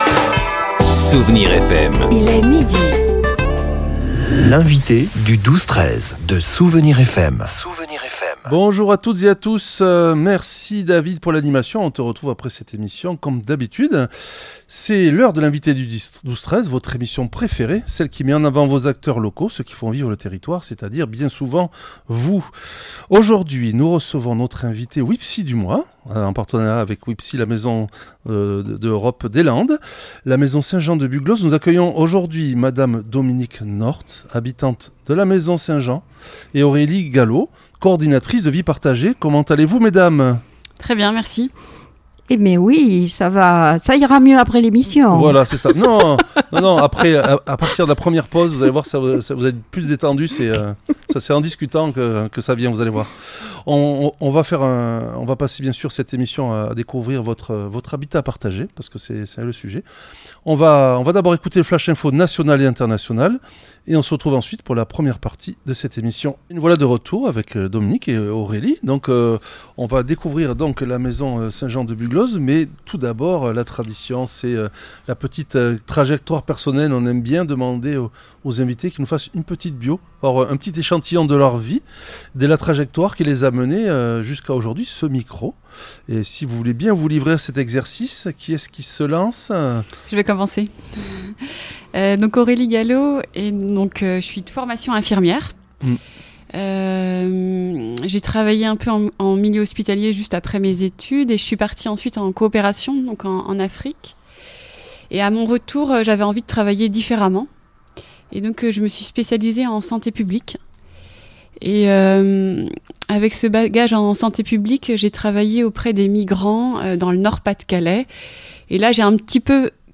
L'entretien a permis de découvrir le quotidien de cette communauté : repas partagés, ateliers couture, apiculture ou jardinage, le programme est dicté par les envies des résidents.